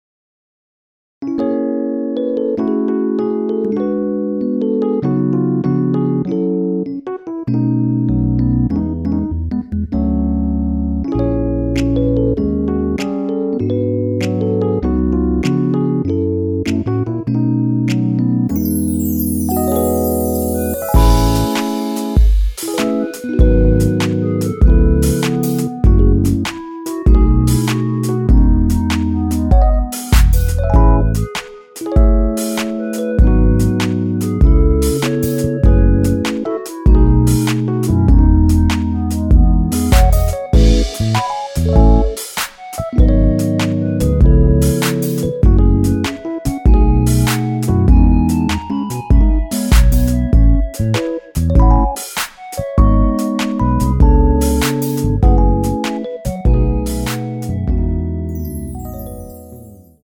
원키 멜로디 포함된 MR입니다.
멜로디 MR이라고 합니다.
앞부분30초, 뒷부분30초씩 편집해서 올려 드리고 있습니다.
중간에 음이 끈어지고 다시 나오는 이유는